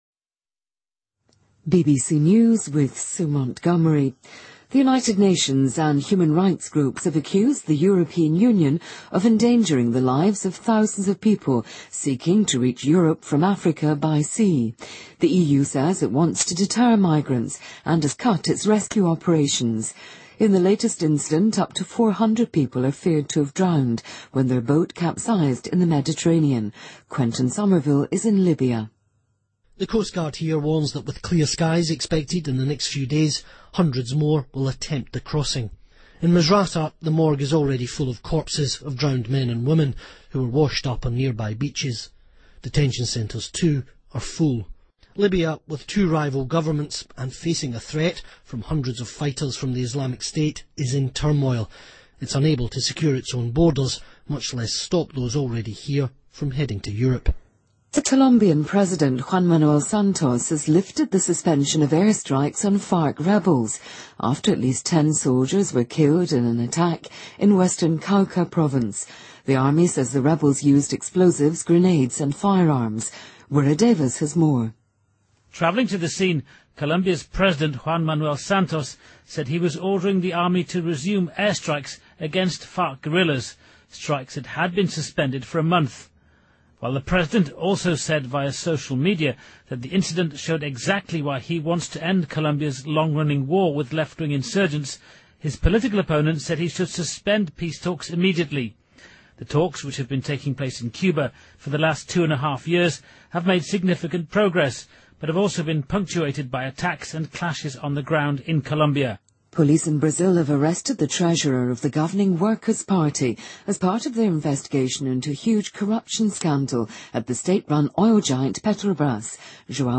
BBC news,土耳其宣布对丹麦外交部提出诉讼
日期:2015-04-16来源:BBC新闻听力 编辑:给力英语BBC频道